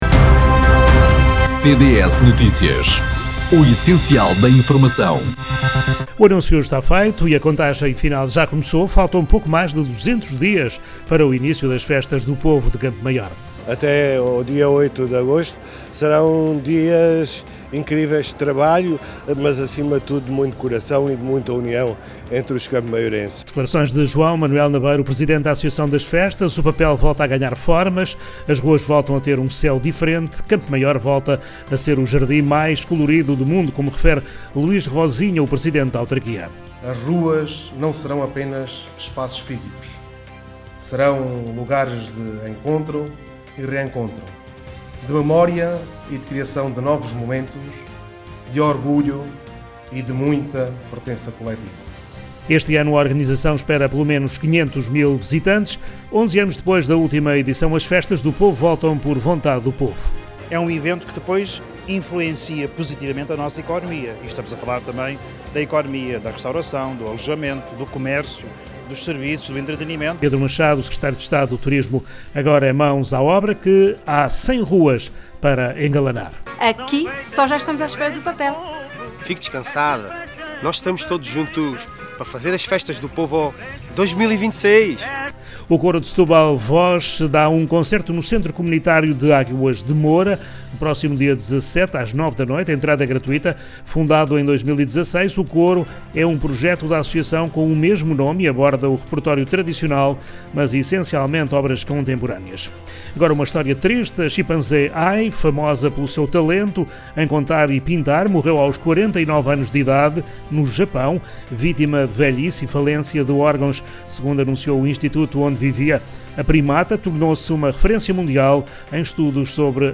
Oiça os intervenientes nas notícias da rádio TDS